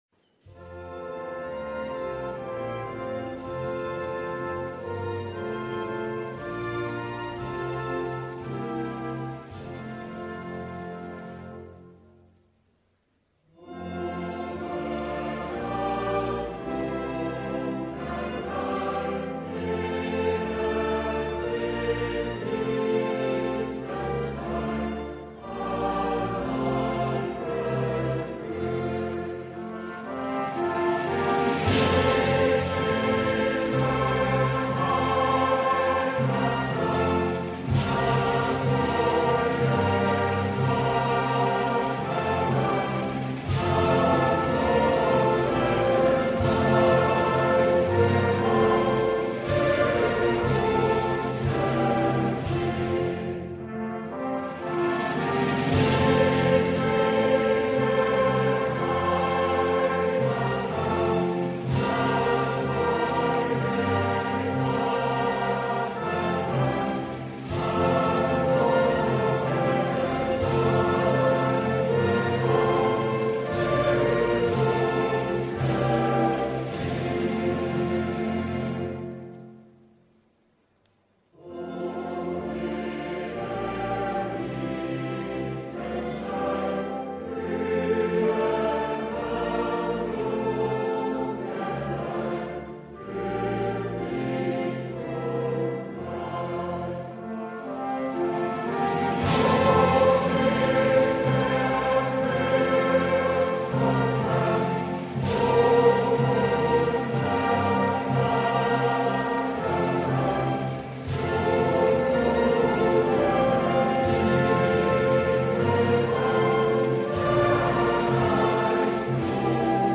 с текстом и музыкой